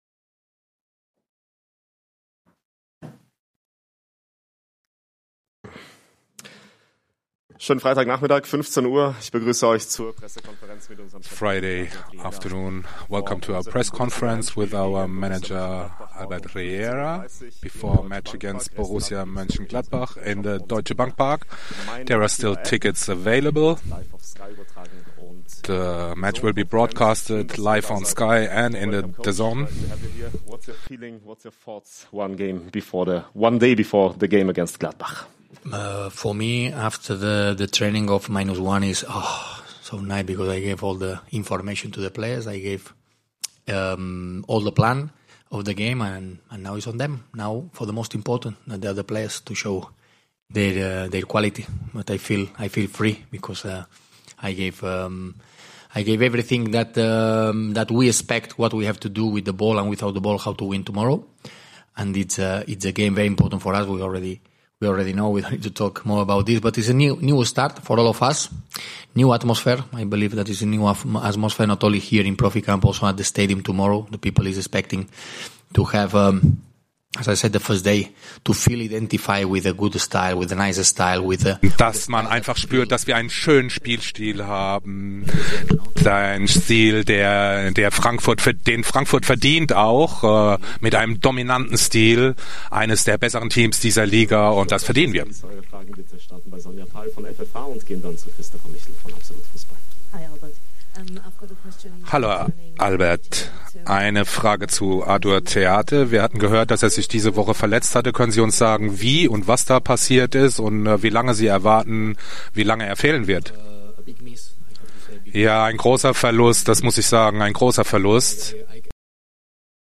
Die Pressekonferenz vor dem ersten Bundesliga-Heimspiel für Cheftrainer Albert Riera.